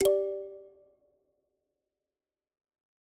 power-plug.ogg